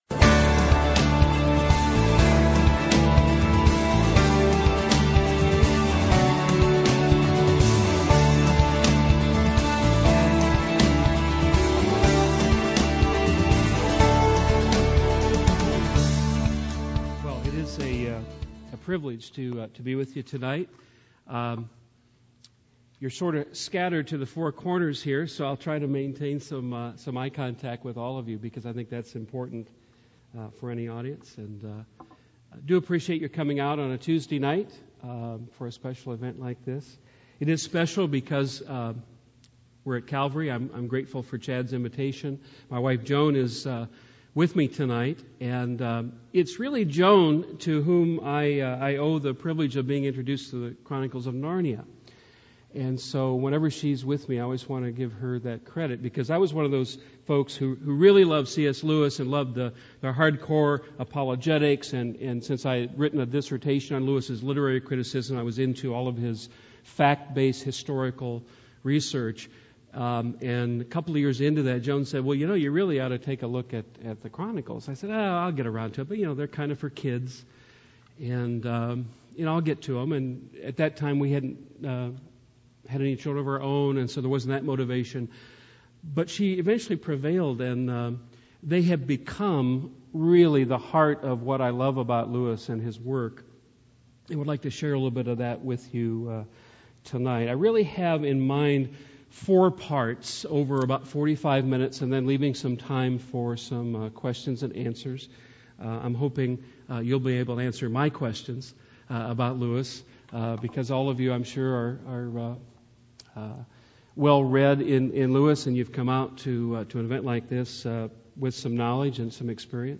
Prince-Caspian_-Chronicles-of-Narnia-Lecture.mp3